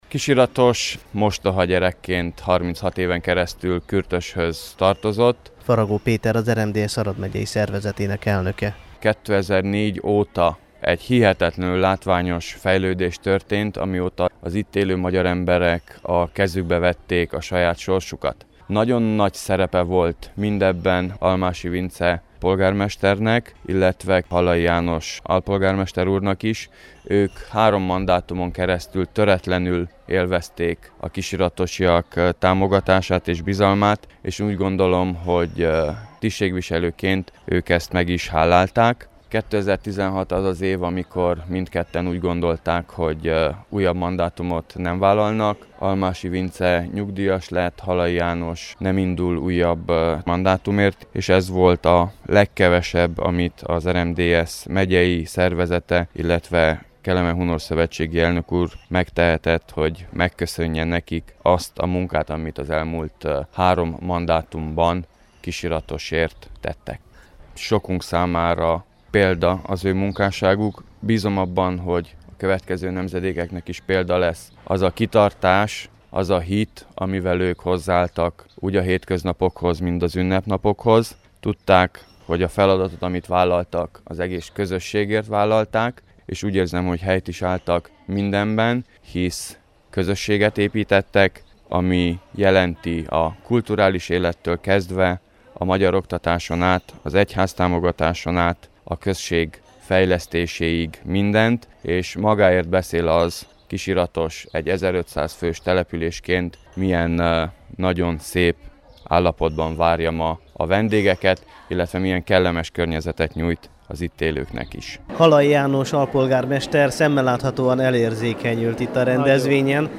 Vasárnap délután a kisiratosi művelődési házban ünnepélyes keretek között mondtak köszönetet a településfejlesztés és a közösségépítés terén kifejtett munkájukért a közigazgatásból idén visszavonuló Almási Vince polgármesternek és Halai János alpolgármesternek, az Arad megyei RMDSZ szervezésében.
összeállítását, amely a Kossuth Rádió Határok nélkül c. műsora számára készült: